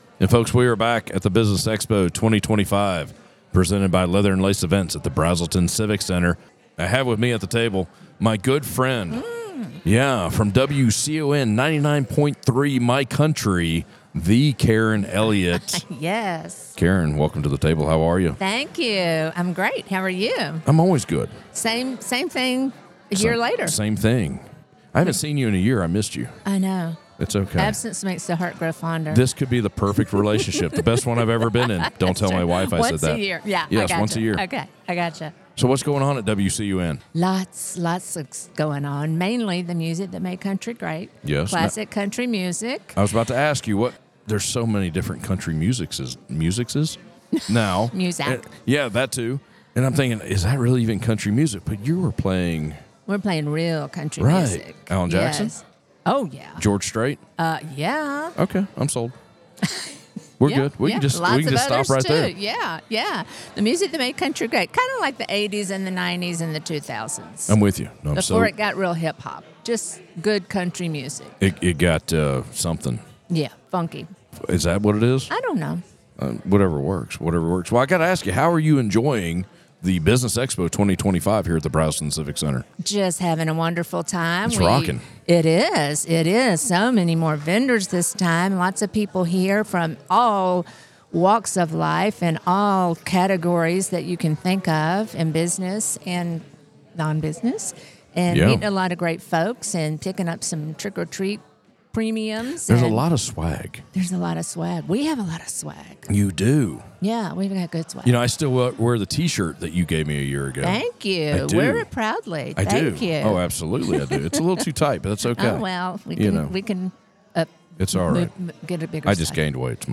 Northeast Georgia Business RadioX – the official Podcast Studio of the Business Expo 2025